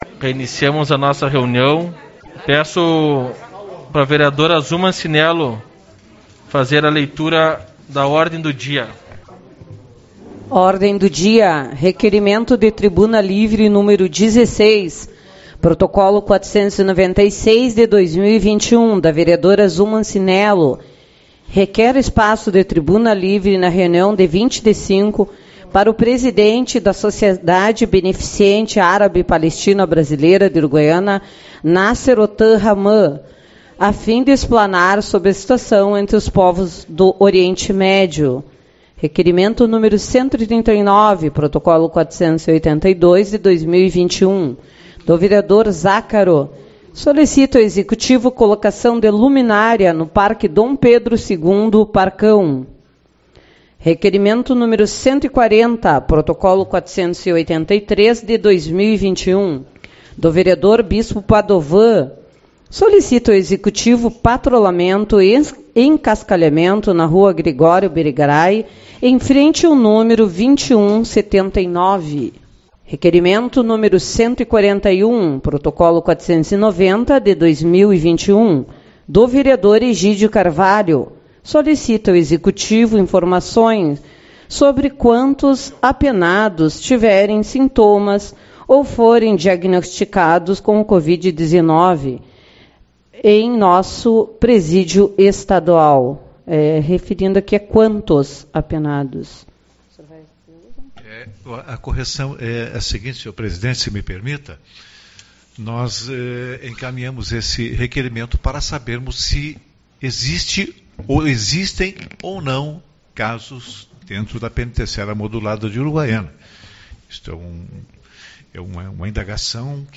18/05 - Reunião Ordinária